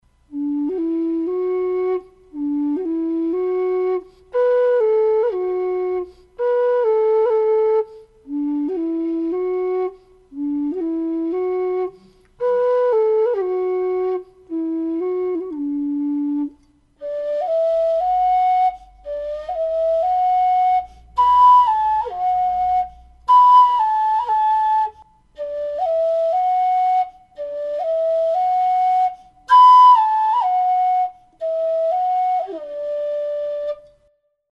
Лоу-вистл D (металл)
Лоу-вистл D (металл) Тональность: D
Модель вистла нижнего диапазона.